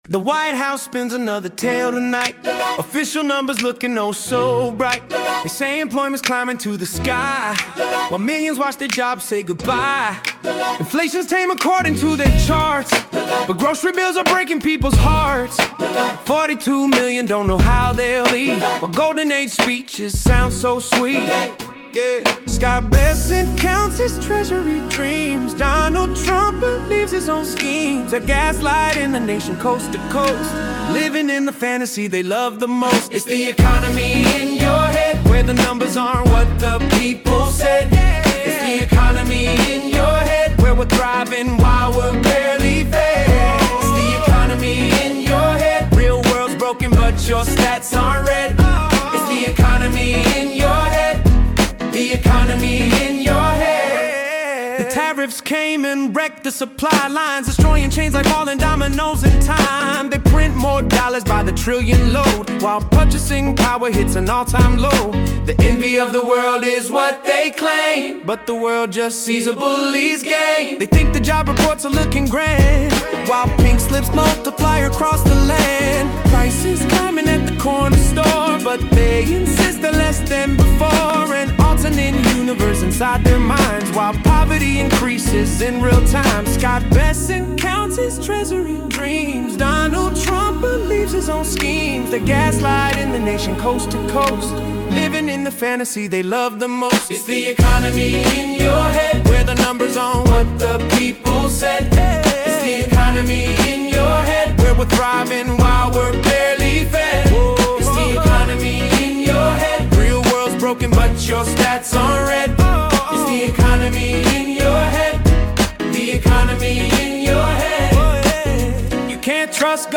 Most vocals are AI generated.
instrument performances by AI.
Tagged Under Hip-Hop Pop Rap